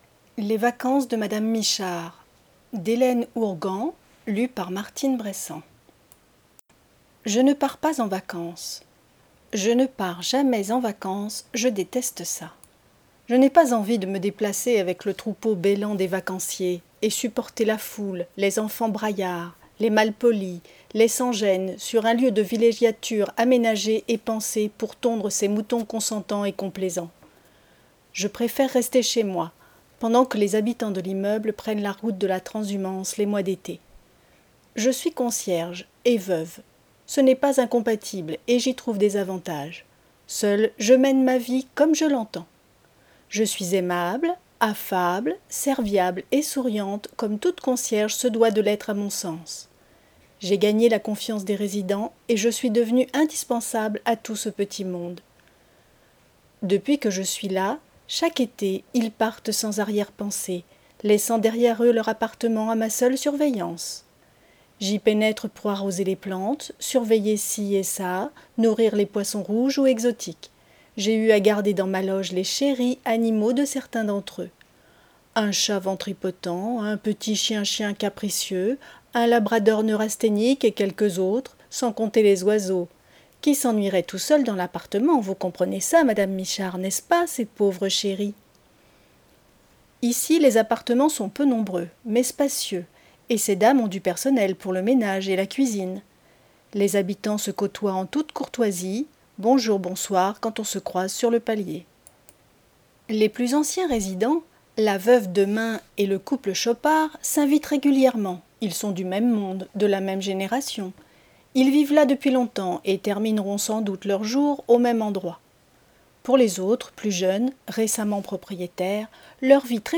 Lecture � haute voix - Les vacances de Madame Michard
NOUVELLE